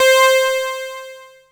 08_juno_release_high.wav